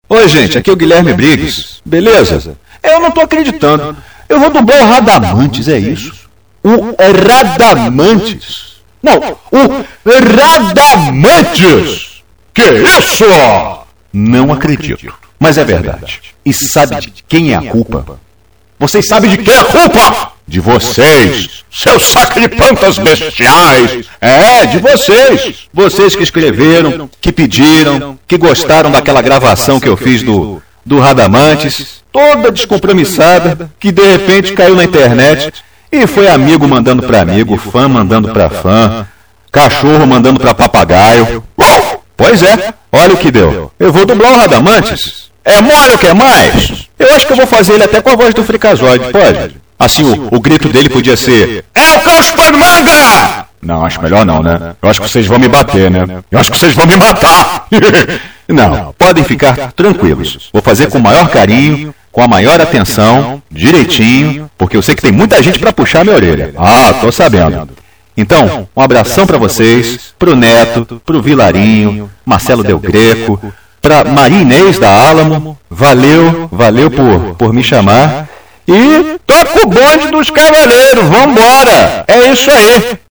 CLIQUE AQUI PARA ESCUTAR UMA MENSAGEM DO GUILHERME BRIGGS, DUBLADOR BRASILEIRO DO RADAMANTHYS DE WYVERN.